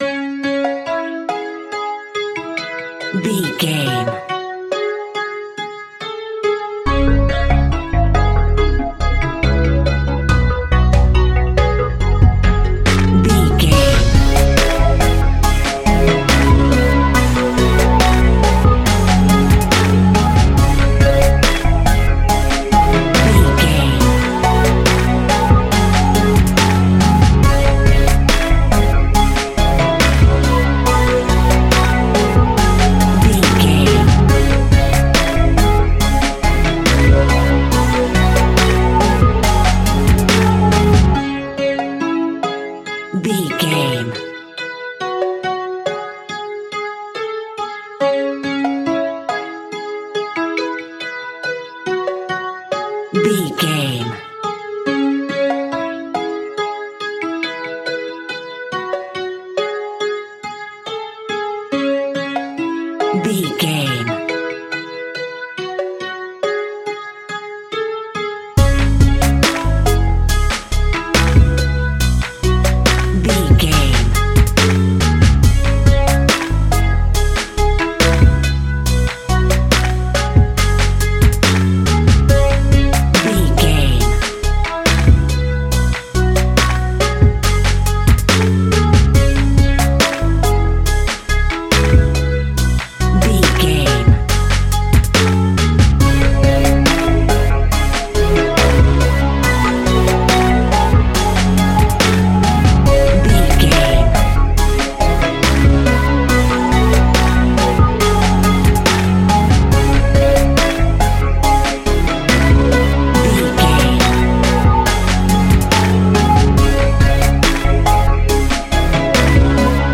Aeolian/Minor
C#
hip hop
chilled
laid back
groove
hip hop drums
hip hop synths
piano
hip hop pads